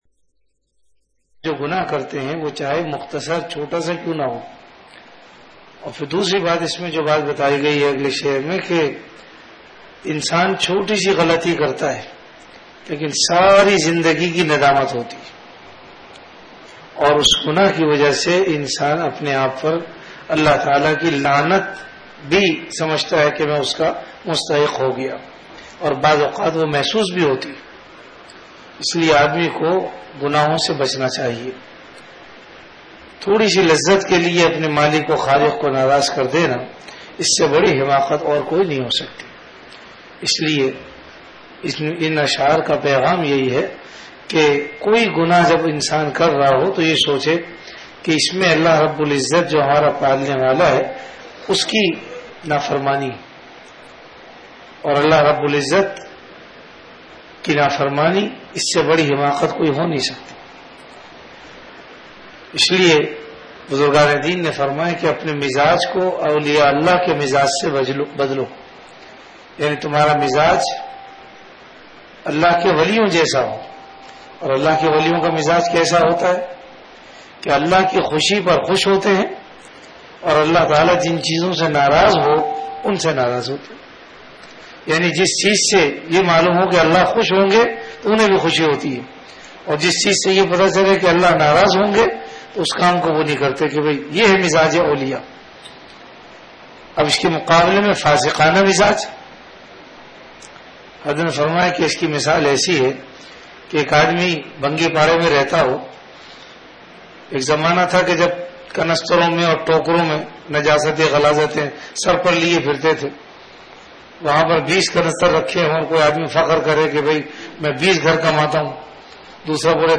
Delivered at Home.
Majlis-e-Zikr · Home Mizaaj e Aulia(Mukhtasir Majlis
After Isha Prayer